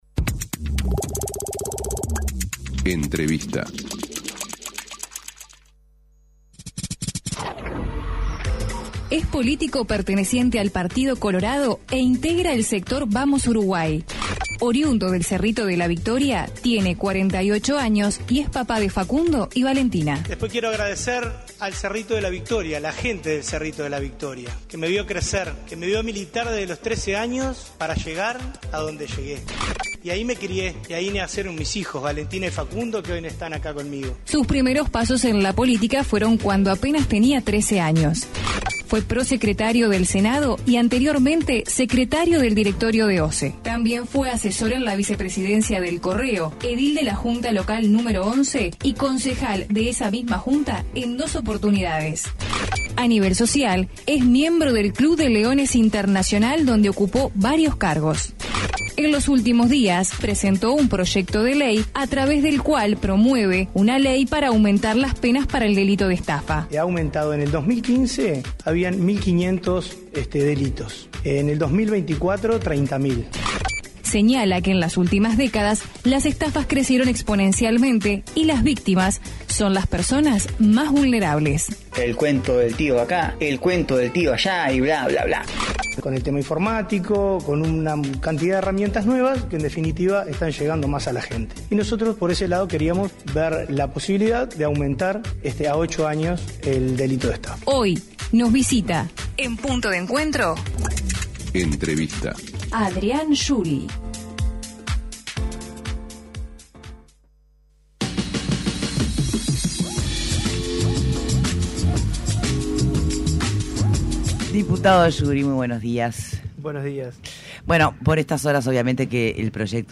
Entrevista a Adrián Juri